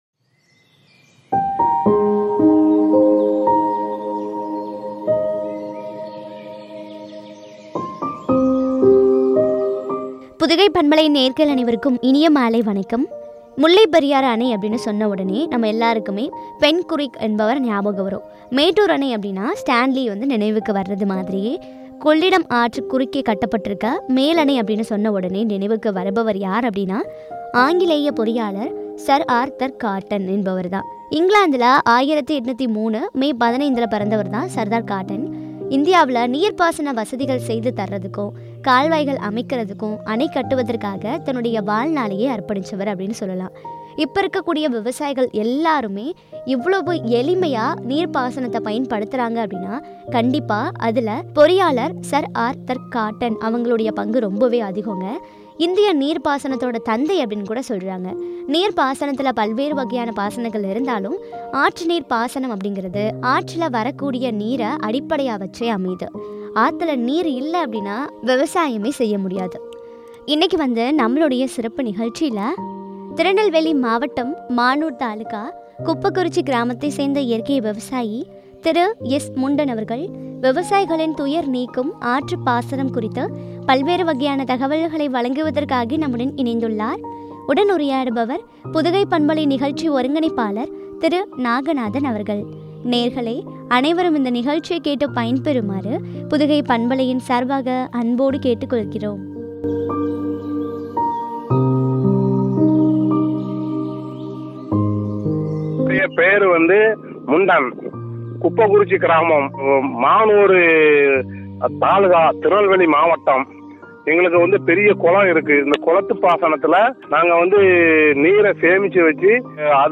” விவசாயிகளின் துயர் நீக்கும் ஆற்றுநீர் பாசனம்” குறித்த வழங்கிய உரையாடல்.